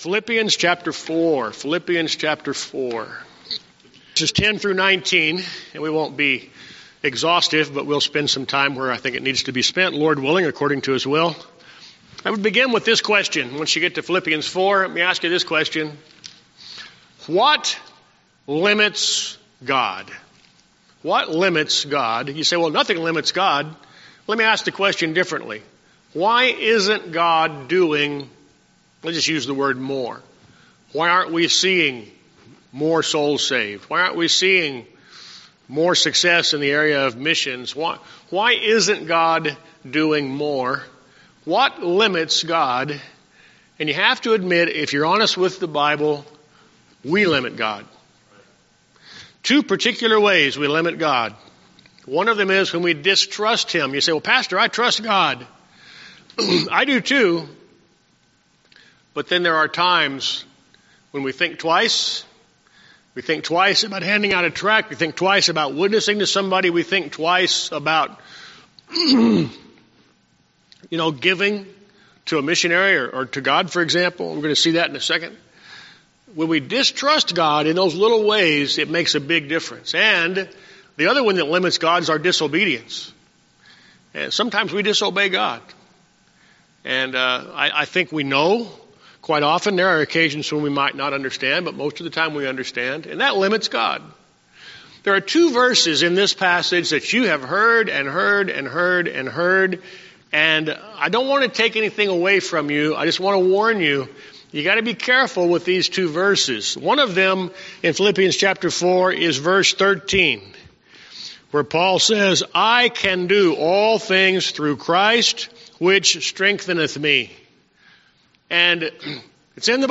Series: Sermons